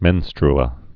(mĕnstr-ə)